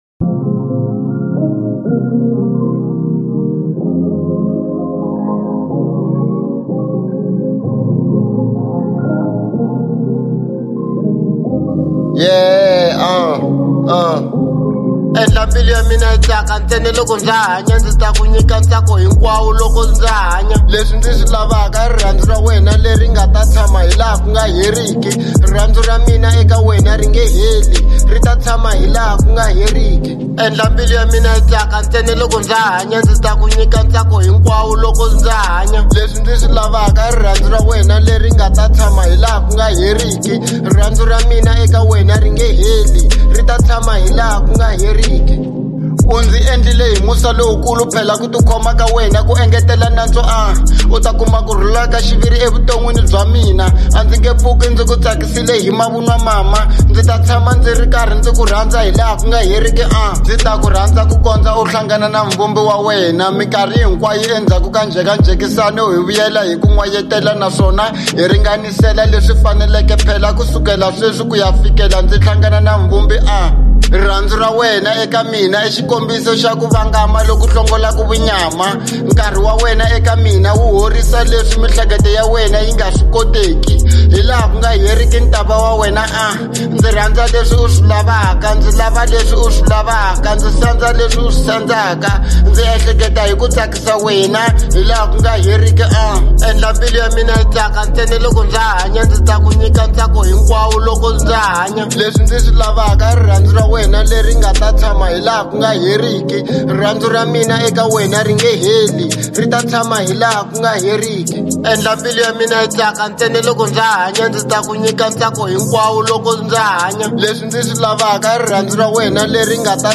03:30 Genre : Hip Hop Size